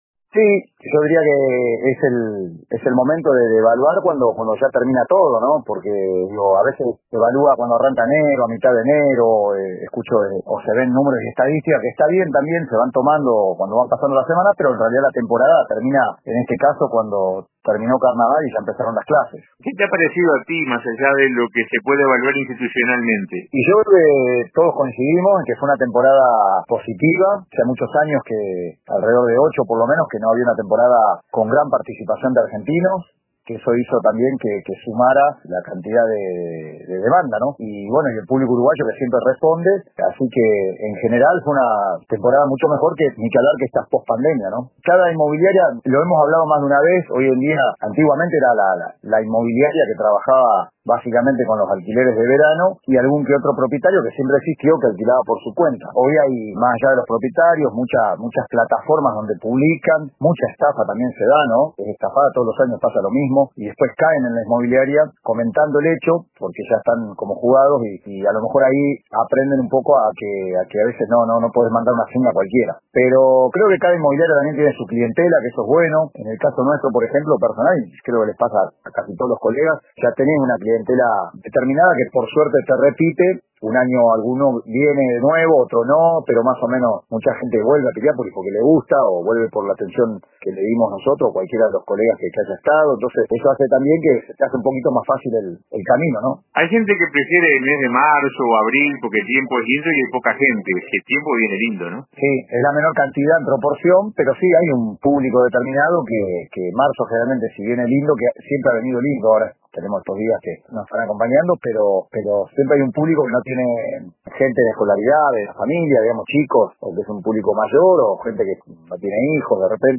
En diálogo con el programa Radio con Todos de RBC